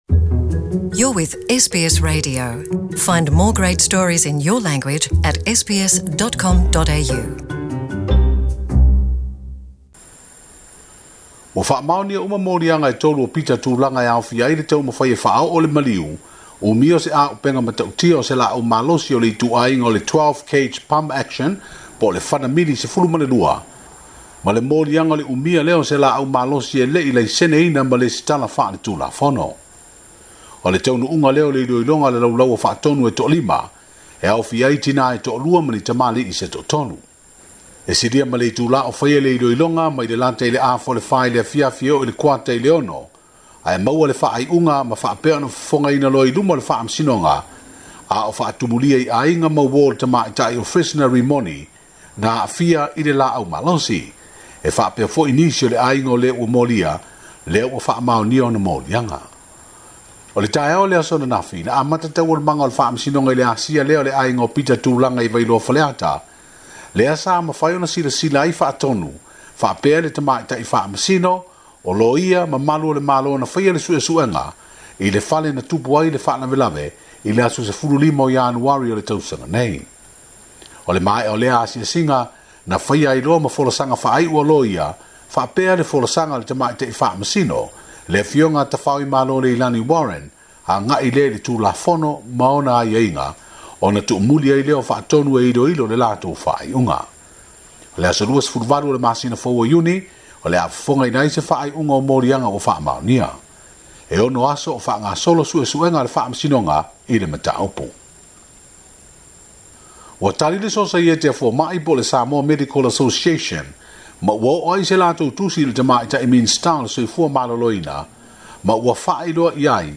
Talafou mai Apia.